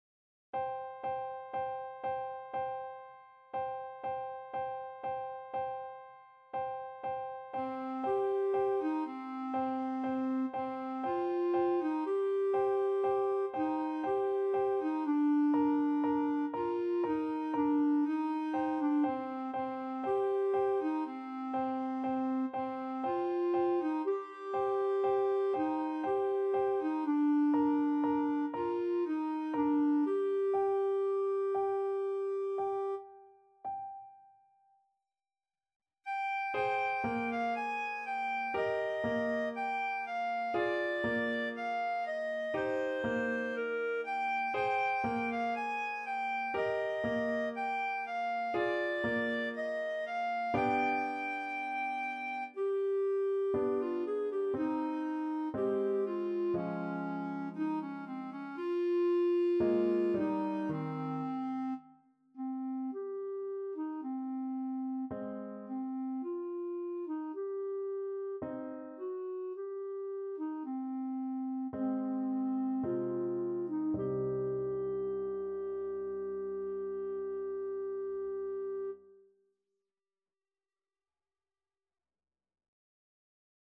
Classical Liszt, Franz La cloche sonne, S.238 Clarinet version
Clarinet
C minor (Sounding Pitch) D minor (Clarinet in Bb) (View more C minor Music for Clarinet )
3/4 (View more 3/4 Music)
Andante sostenuto =60
Classical (View more Classical Clarinet Music)